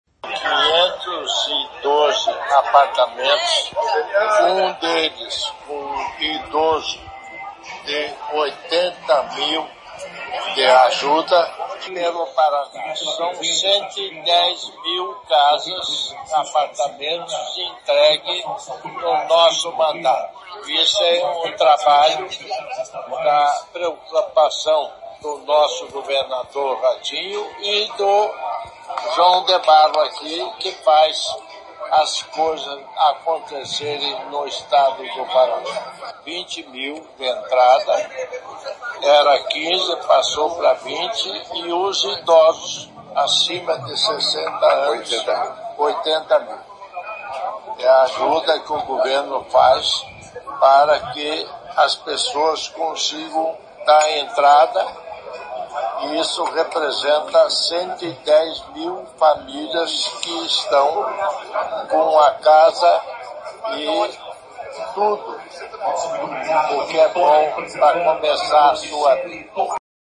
Sonora do governador em exercício, Darci Piana, sobre unidades do Casa Fácil Paraná em Londrina